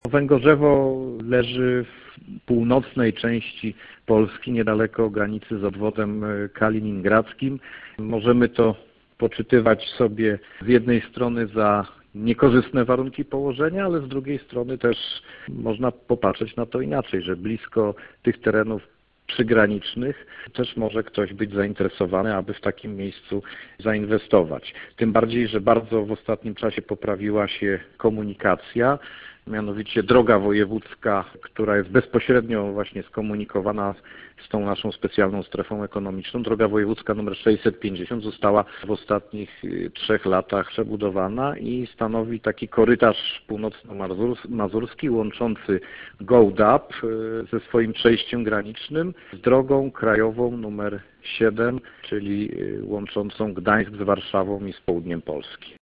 – Jesteśmy blisko granicy z Obwodem Kaliningradzki, ale mamy też dobre połączenie z drogą krajową nr 7 – wylicza burmistrz.